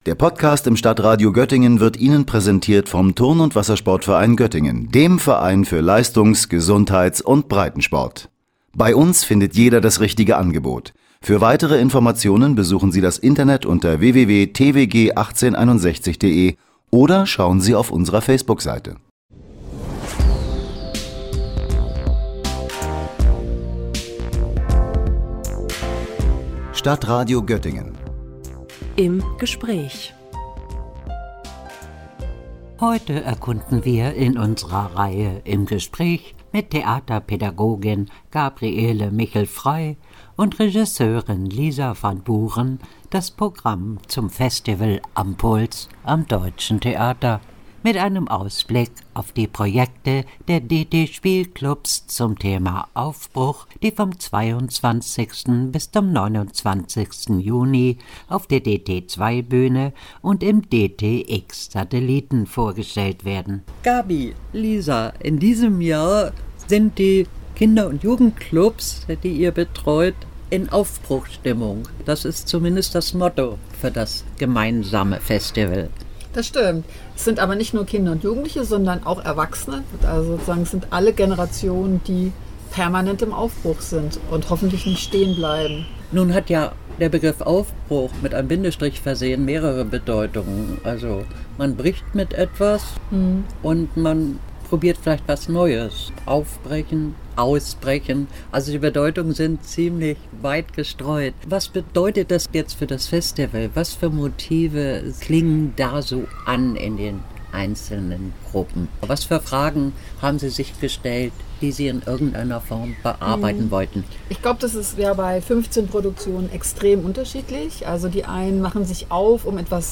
„Auf-Bruch“ mit den Spielclubs des Deutschen Theaters zum Theaterfestival „Am Puls“ – Gespräch